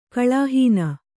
♪ kaḷāhīna